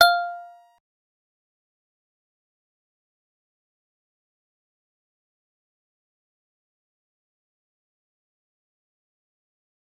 G_Musicbox-F5-pp.wav